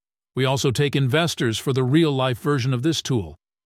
anouncer_lvl1_02.ogg